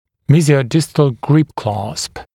[ˌmiːzɪəu’dɪstl grɪp klɑːsp][ˌми:зиоу’дистл грип кла:сп]перекидной удерживающий кламмер